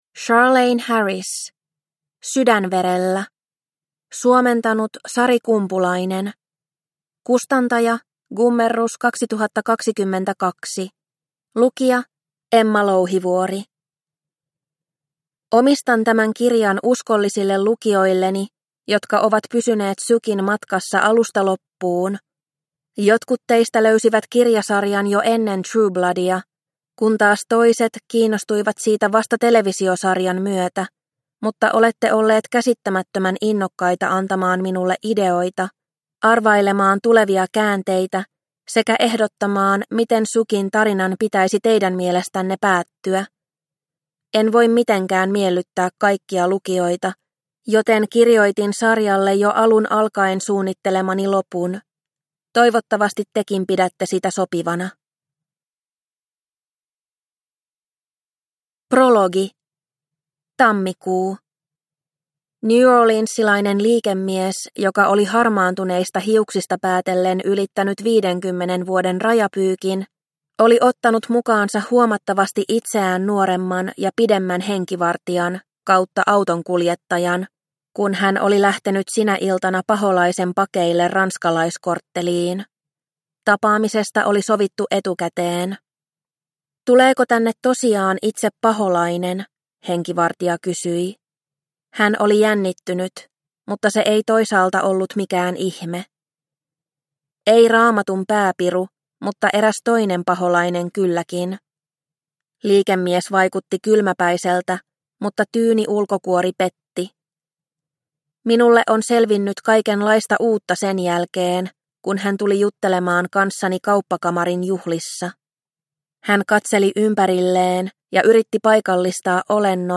Sydänverellä – Ljudbok – Laddas ner